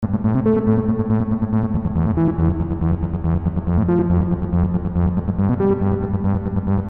These samples are primarily designed for the Dance eJay series of programs i.e. They are all in A-minor and run at 140BPM.
Basspower_2 - A low lead synth with delay and reverb.
basspower_2.mp3